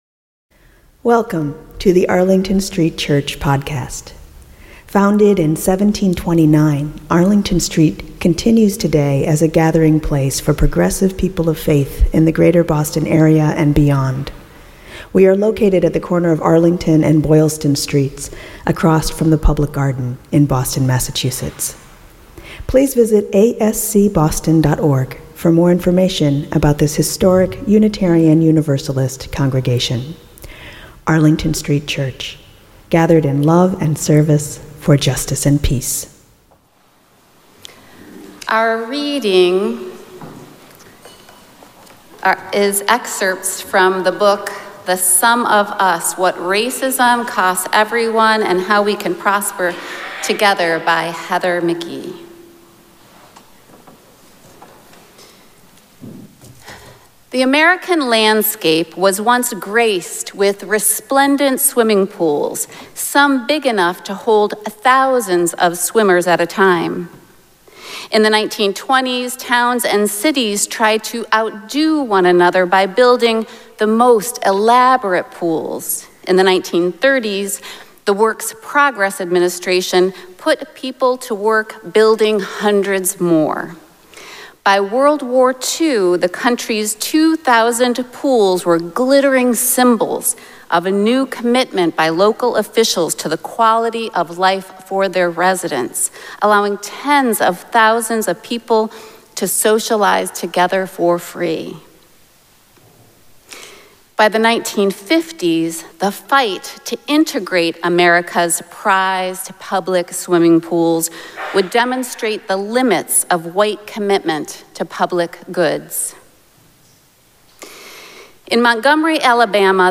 The sermon podcast from Arlington Street Church delivers our weekly sermon to listeners around the world.